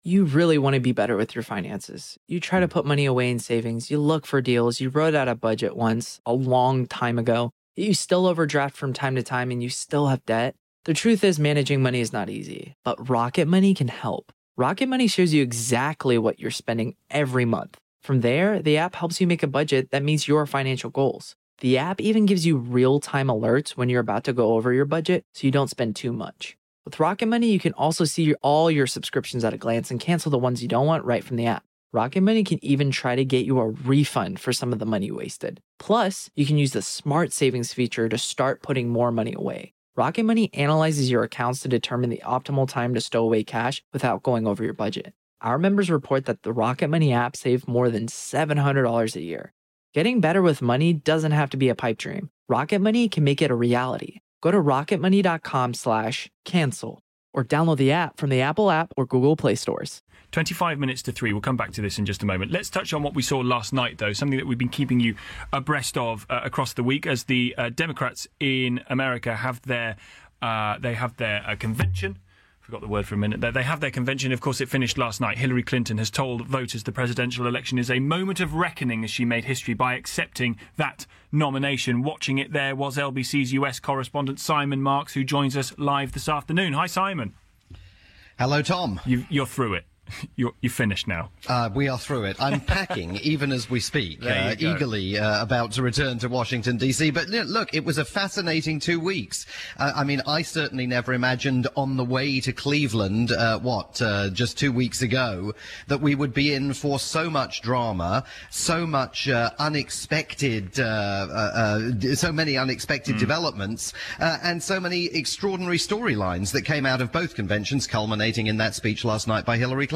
wrap-up report on the Democratic Convention in Philadelphia, as heard on LBC